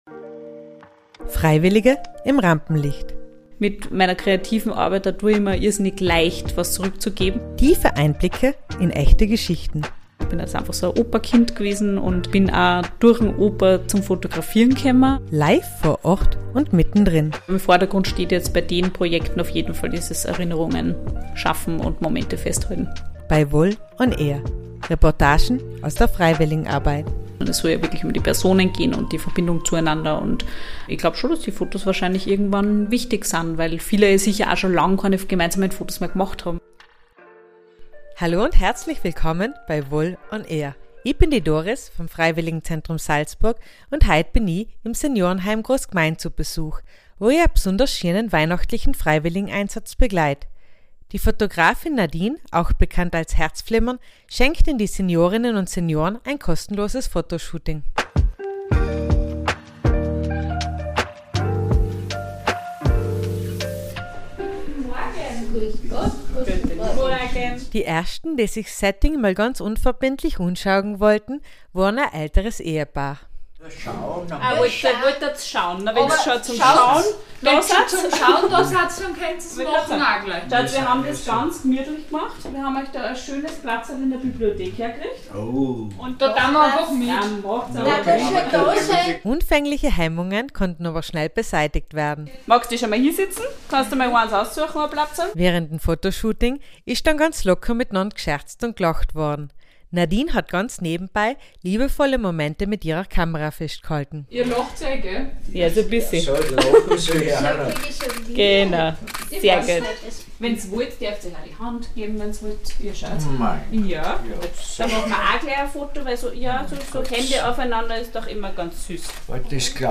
einen besonders berührenden Tag im Seniorenheim Großgmain